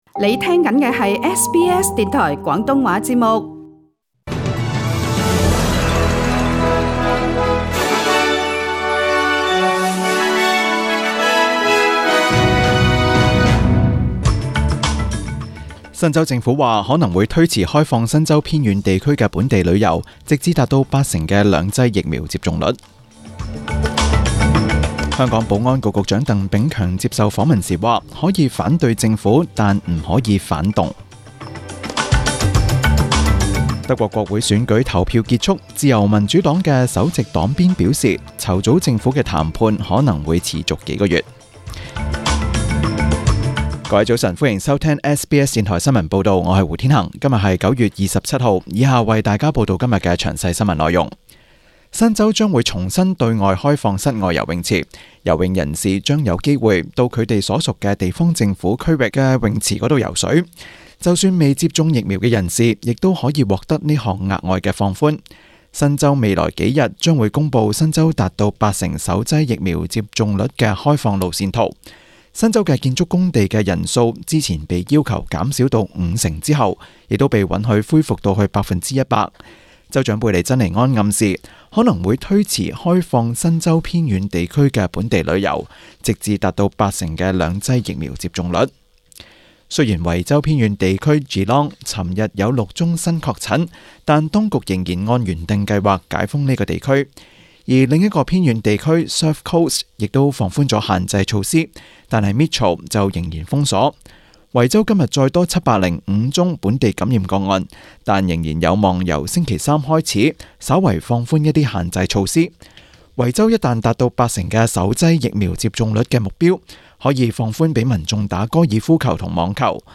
SBS 中文新闻 （九月二十七日）
请收听本台为大家准备的详尽早晨新闻。